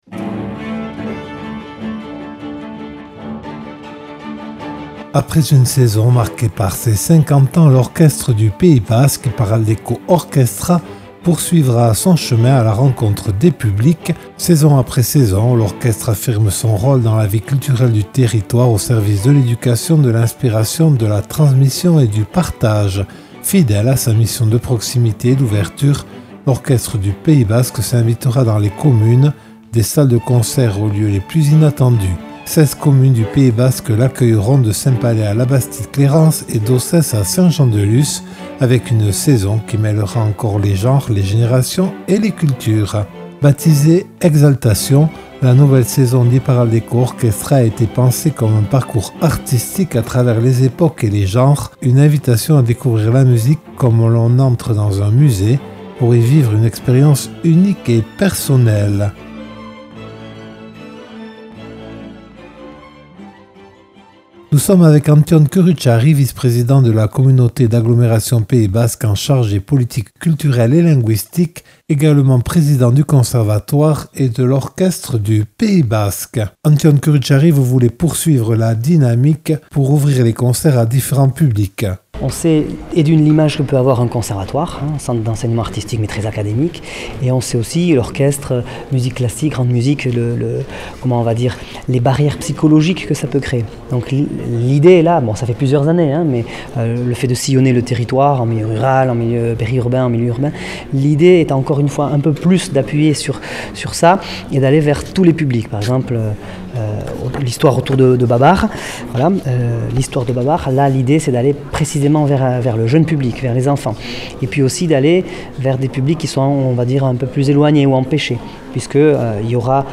Entretien avec Antton Curutcharry, vice-président en charge de la culture à la Communauté d’agglomération (CAPB).